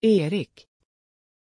Pronuncia di Èric
pronunciation-èric-sv.mp3